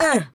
22 RSS-VOX.wav